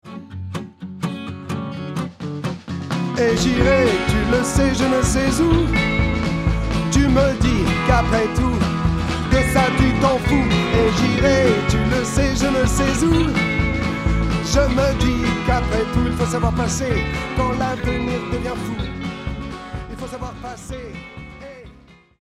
Soul 1.